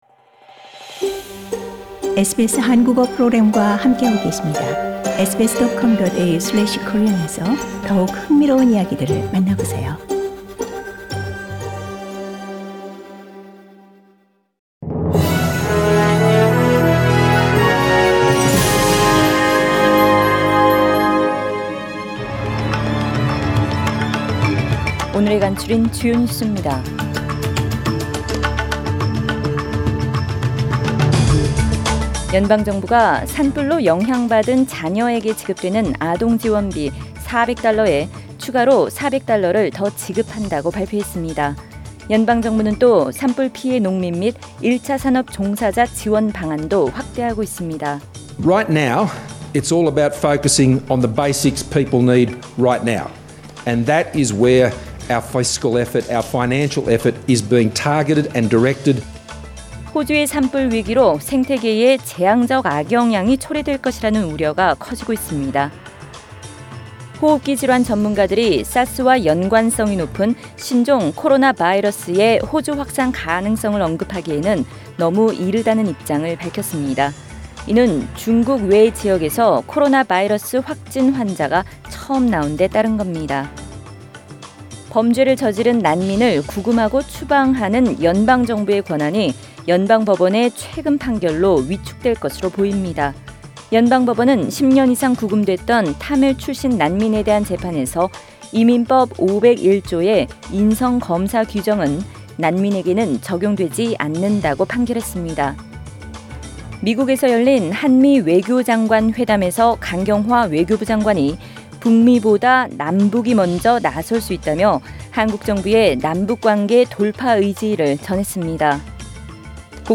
SBS Korean News Source: SBS Korean